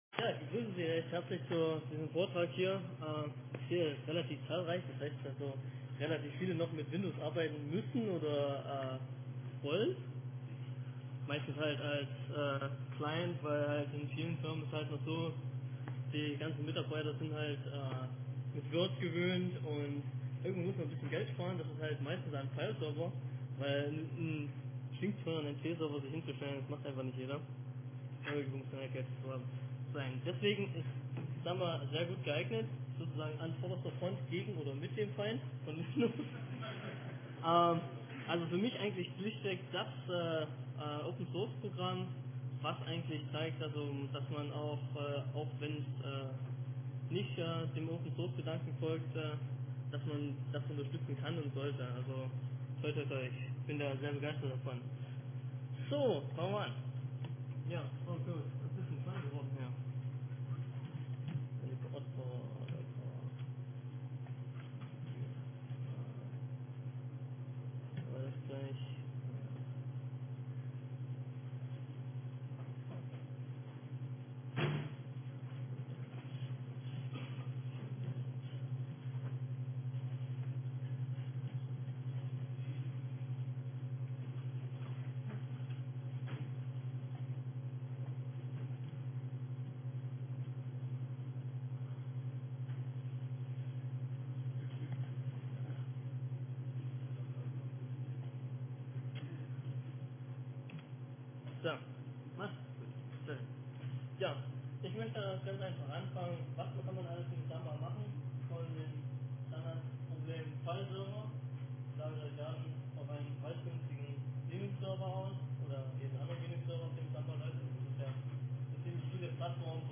MP3-Mittschnitt vom Vortrag als MP3 (32 kbit/s) (10 MByte) MP3-Mittschnitt vom Vortrag als MP3 (16 kbit/s) (5 MByte)